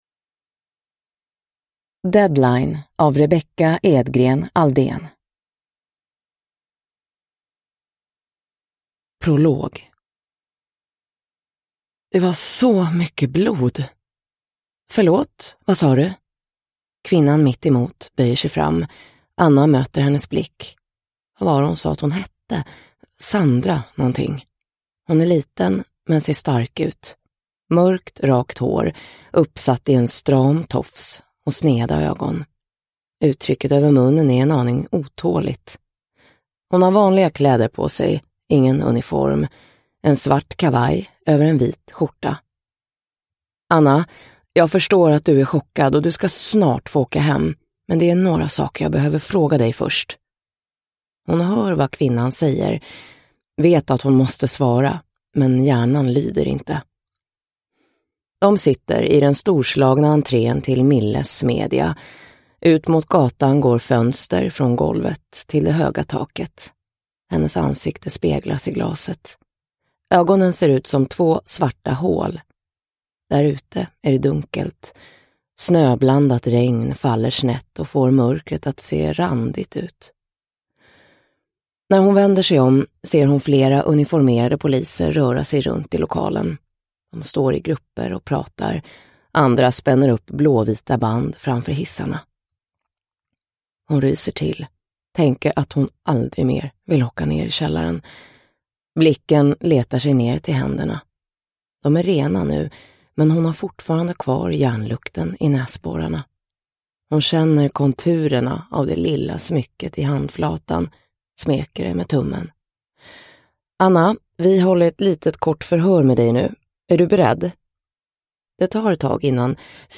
Deadline – Ljudbok – Laddas ner